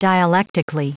Prononciation du mot dialectically en anglais (fichier audio)
Prononciation du mot : dialectically
dialectically.wav